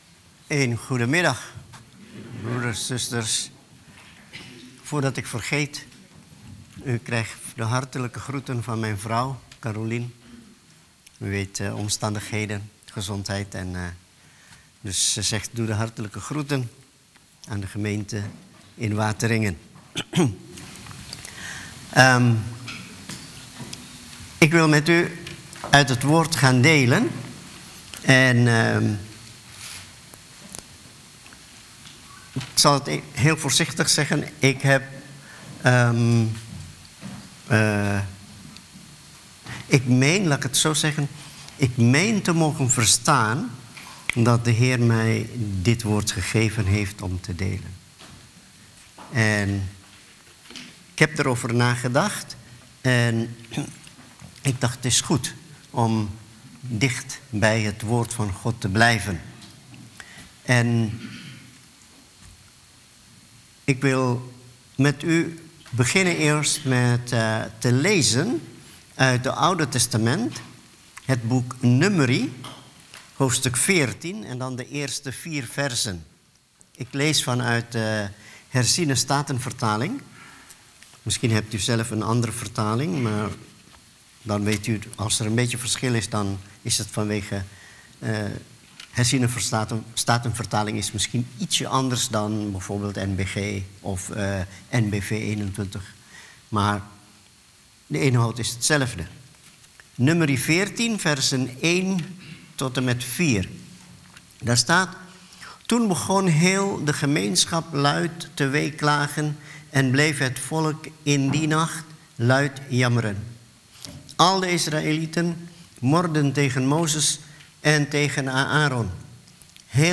Preek